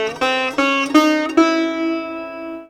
SITAR LINE21.wav